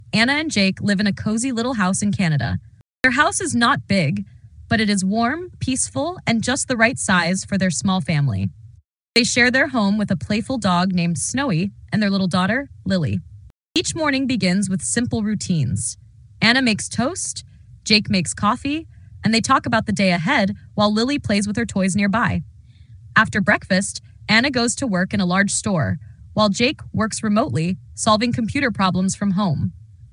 Practice stress and reduction in pronunciation using the marked-up text below. Stressed syllables are indicated with bold and uppercase letters, while reduced syllables are shown in lowercase with schwa sounds.